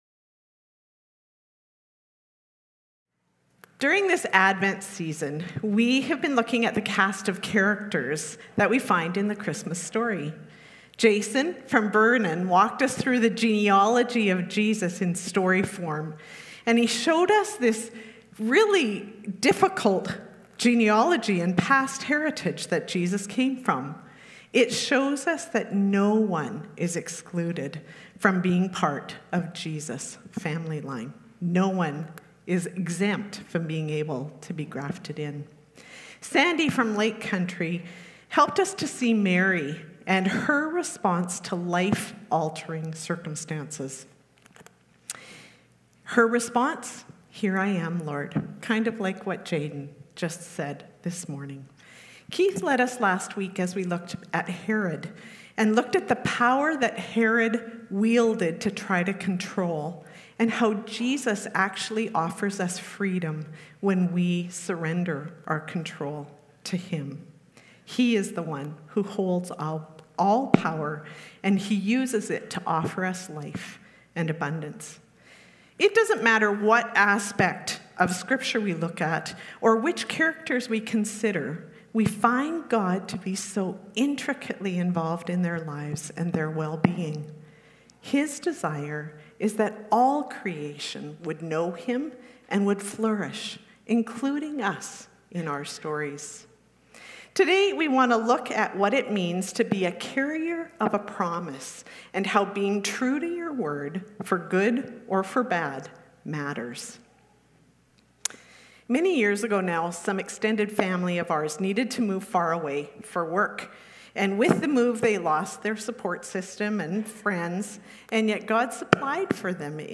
SERMONS | Mission Creek Alliance Church